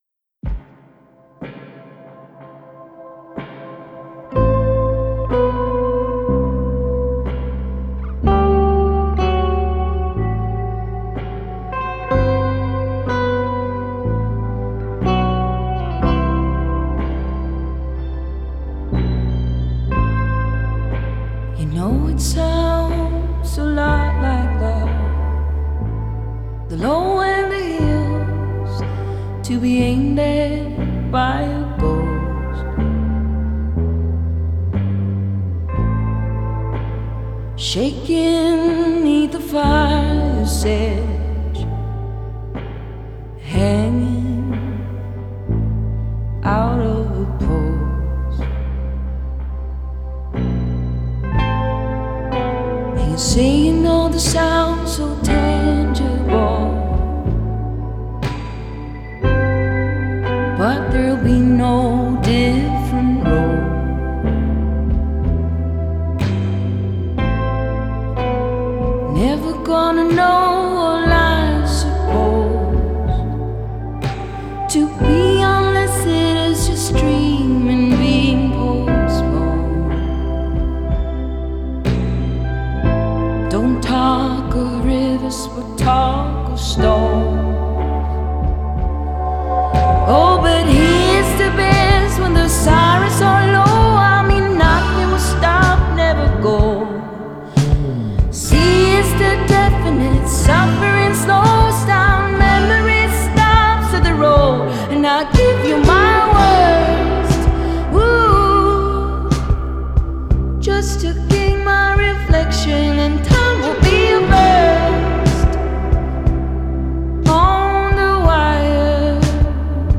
Genre: Pop Folk